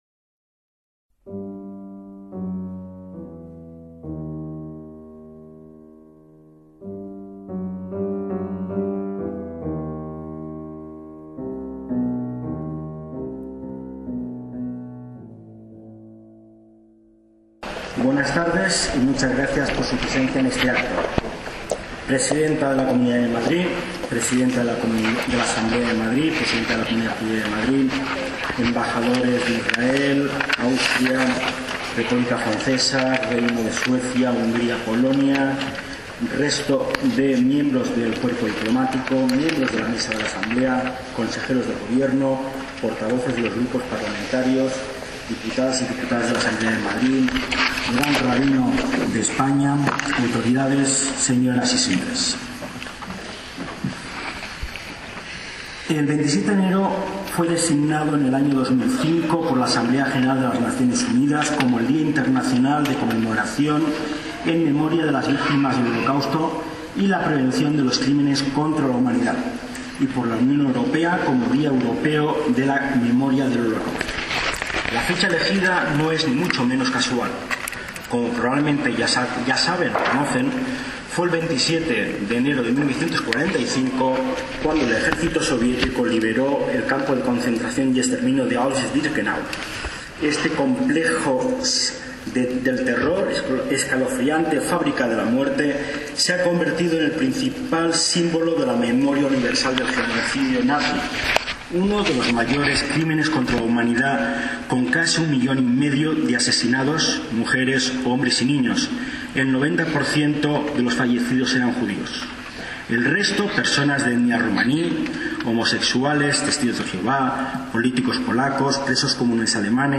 ACTOS EN DIRECTO - El 31 de enero de 2023 se celebró en la Asamblea de la Comunidad de Madrid el Acto en Recuerdo del Holocausto, cuya grabación íntegra les traemos.